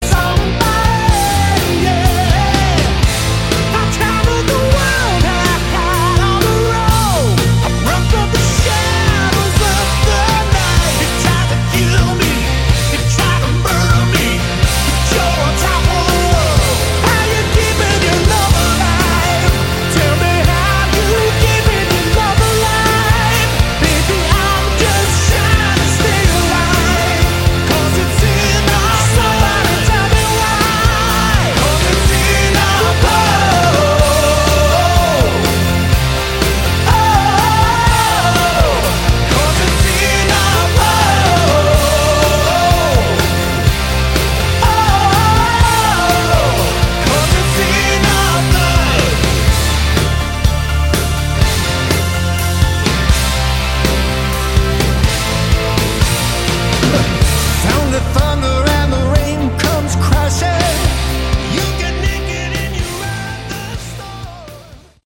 Category: AOR/ Melodic Rock